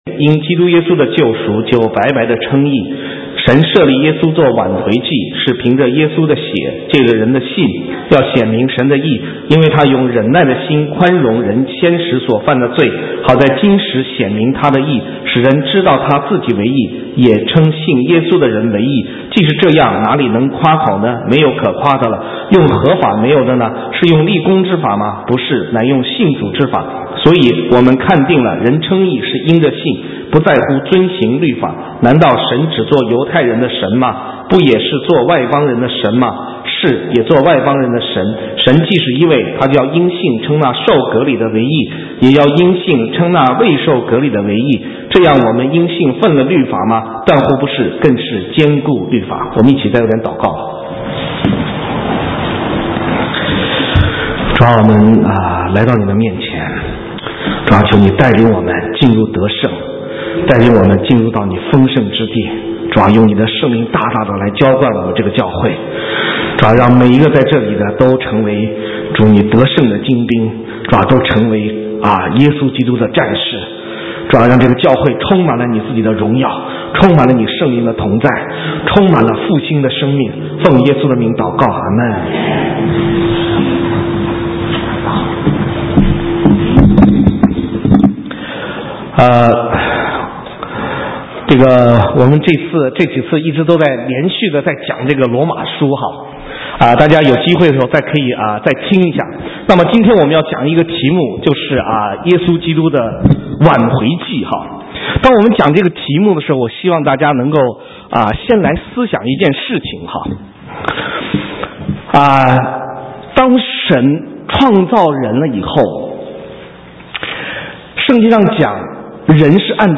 神州宣教--讲道录音 浏览：挽回祭 (2012-03-04)